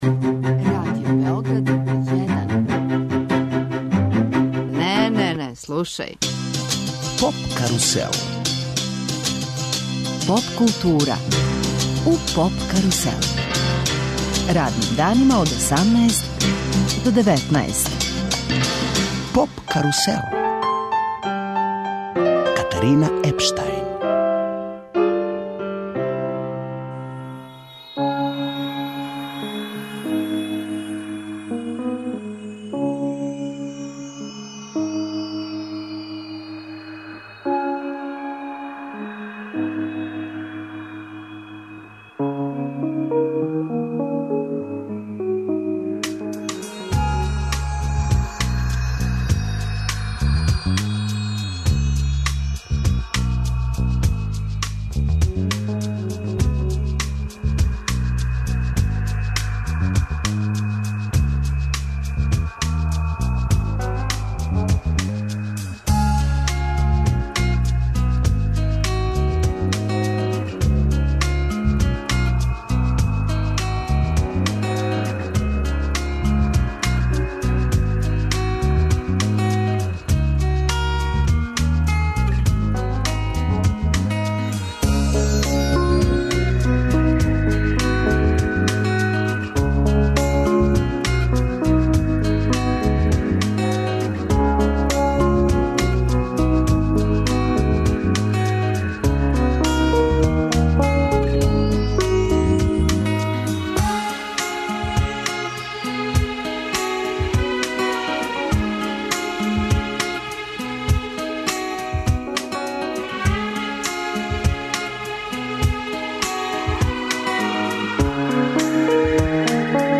Свирају уживо и преслушавамо албум...